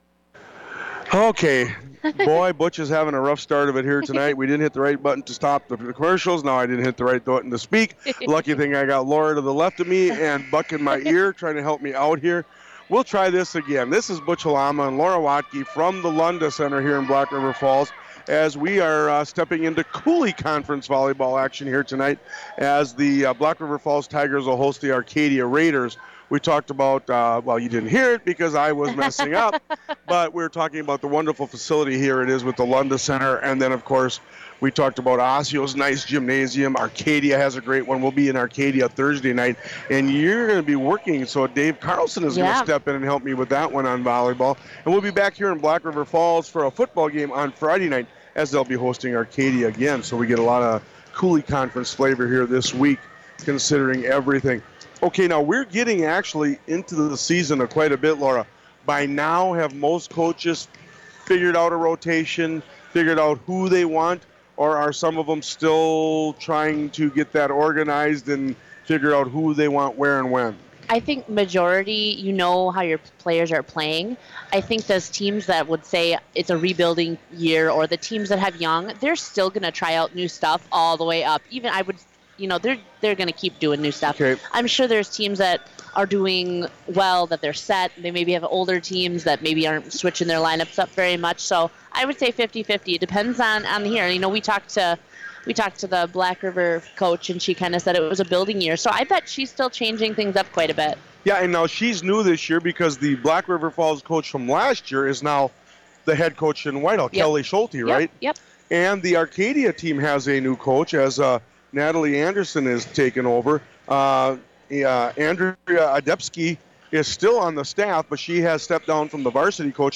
Western Wisconsin High School Sports Broadcast